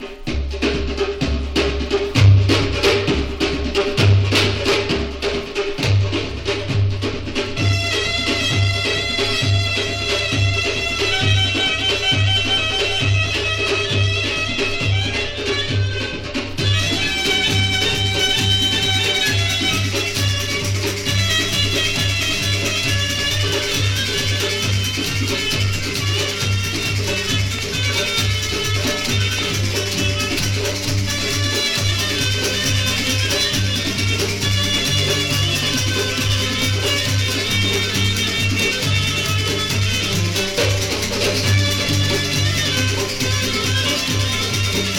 独特とも神秘的とも思えるエキゾティシズムをまとった魅力的な楽曲が緩急自在な演奏によりうねります。
※盤質によるプチプチノイズ有
World, Middle East　USA　12inchレコード　33rpm　Stereo
盤擦れ　プチプチノイズ